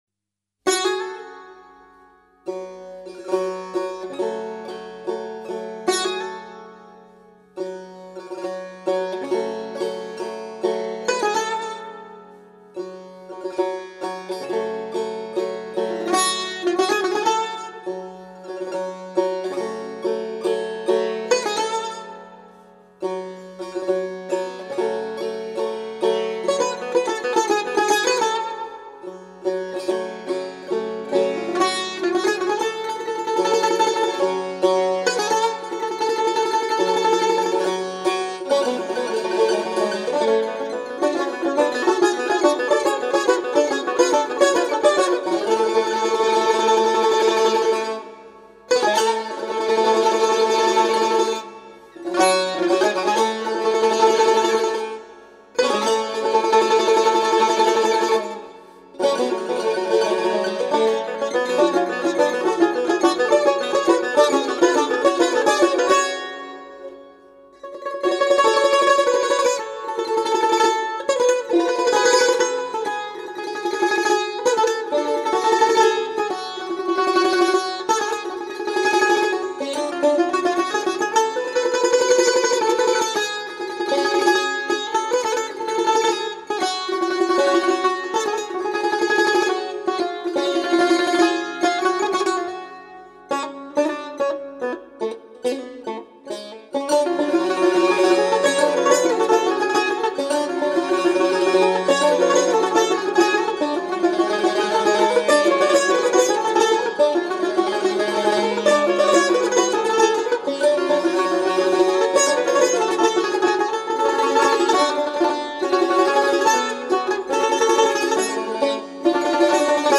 Instrumental Music